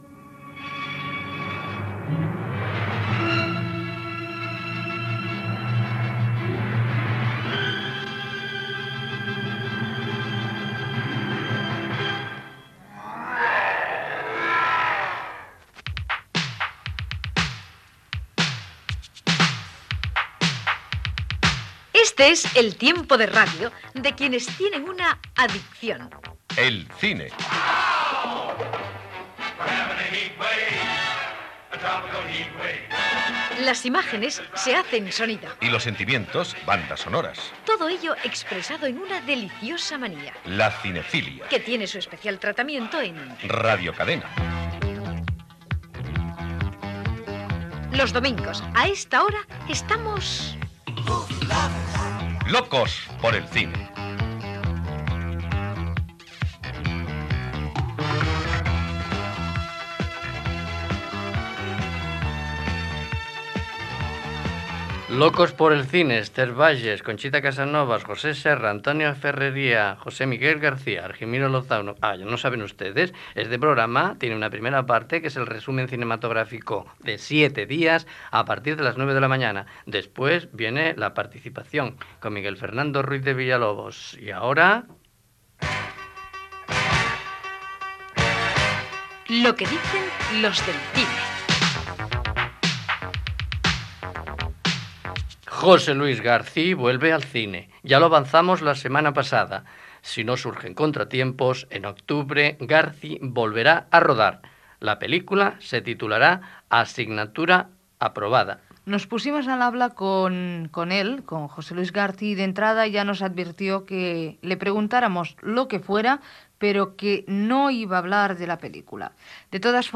Careta del programa, equip, entrevista al director José Luis Garci que rodará "Asignatura aprobada (1987), records cinematogràfics de Barcelona
Cultura